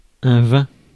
Ääntäminen
Synonyymit pinard purée septembrale gibolin pivois bourgogne alsace Ääntäminen France (Paris): IPA: [ɛ̃ vɛ̃] Tuntematon aksentti: IPA: /vɛ̃/ Haettu sana löytyi näillä lähdekielillä: ranska Käännös Substantiivit 1. vein Suku: m .